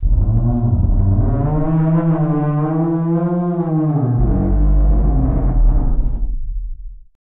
MOAN EL 11.wav